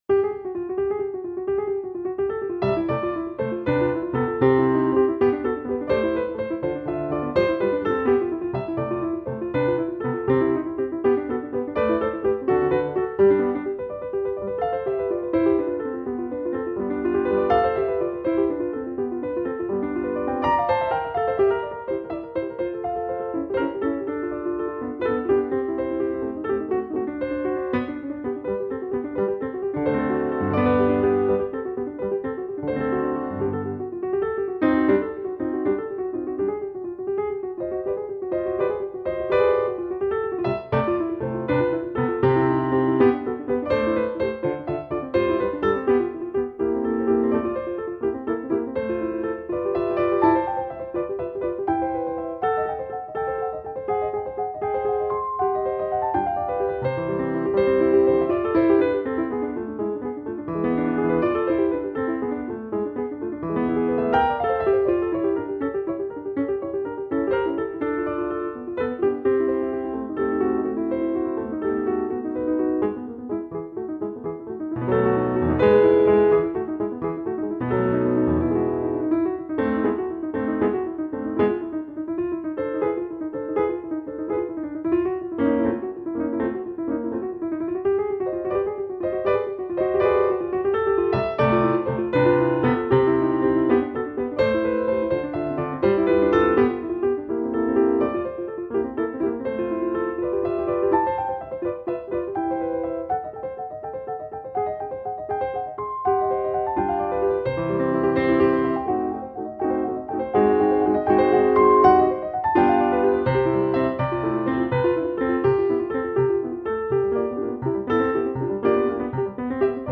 Spinning-Song.mp3